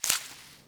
harvest_5.wav